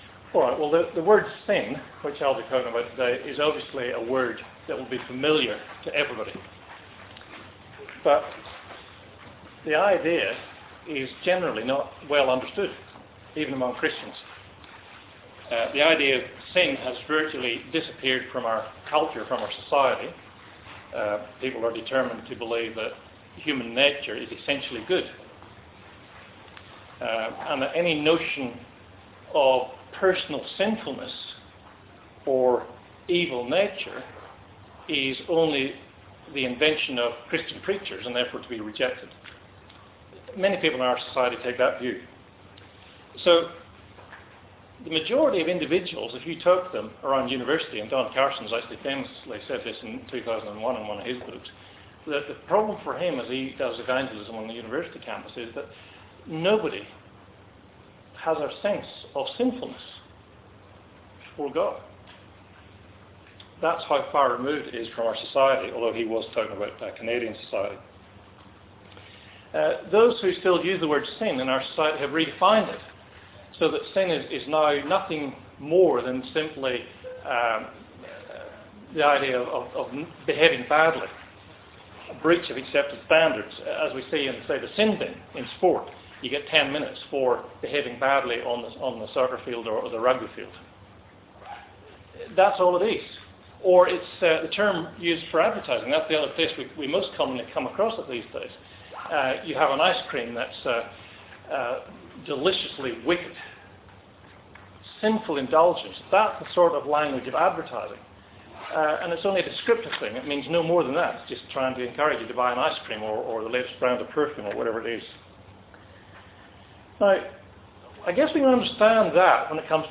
Ephesians 2:1-10 Talk Type: Bible Talk « Trinity